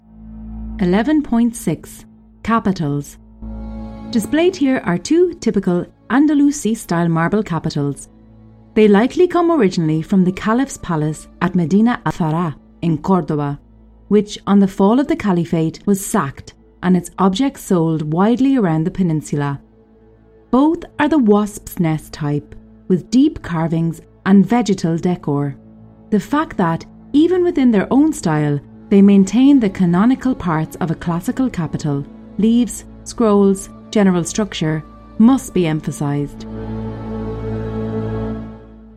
78_audio_tour_Archaeology_Museum_Badajoz_EN.mp3